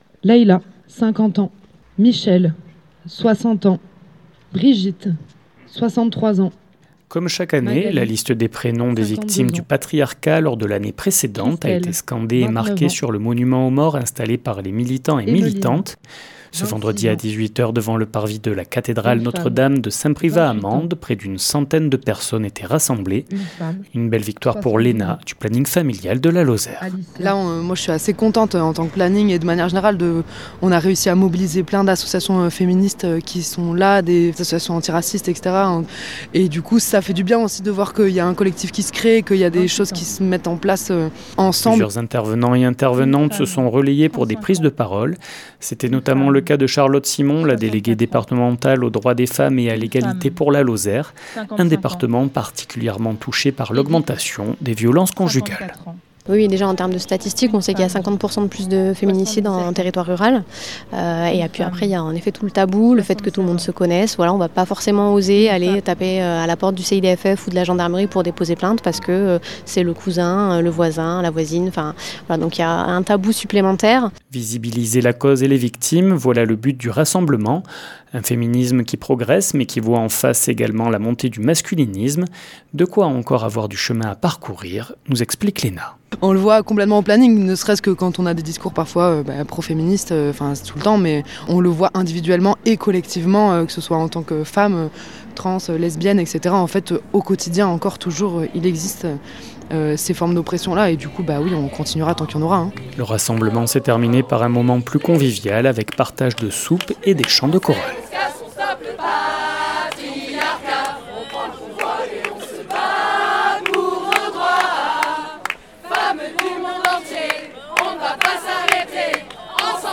Elle avait été reportée fin novembre pour des questions météorologiques, la « nuit féministe » s’est bien tenu vendredi dernier dans le cadre du weekend du 8 mars, la journée internationale des droits des femmes. Un rassemblement organisé par le planning familial avec la participation de l’association LGBTQI+ Tapage, le collectif égalité homme femme 48 ou encore RESF et qui s’est tenu cette année sur la place Urbain V à Mende, un endroit symbolique entre la cathédrale, la préfecture et le tribunal.
Reportage